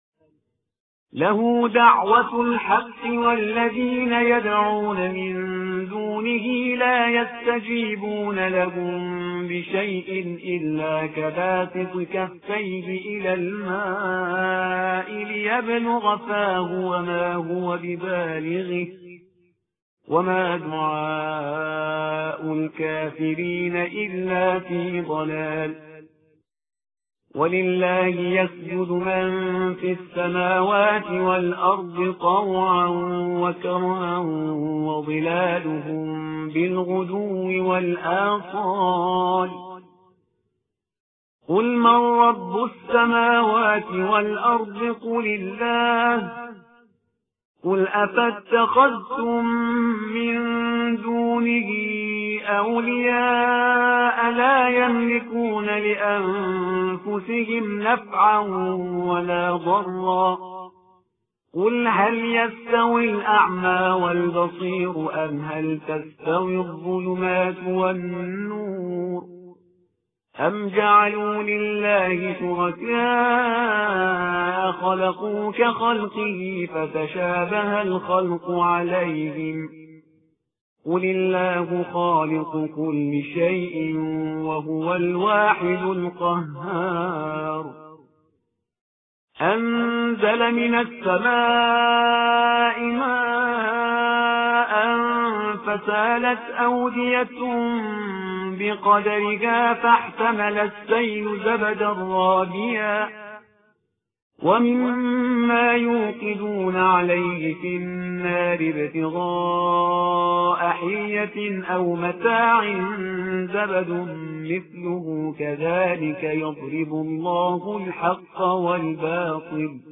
صوت/ترتیل صفحه ۲۵۱ قرآن